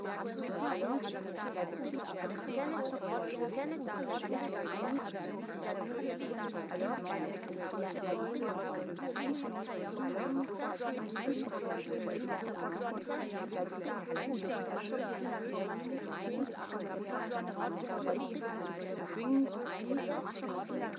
Le signal est ici la voix française de l’ISTS (NFIM french) et le bruit un mélange de 4 ISTS, le tout de -12dB à +12dB de RSB + silence.
Ce qui se passe à RSB -7dB au micro de référence avec cette aide auditive :
RSB -7dB au micro de référence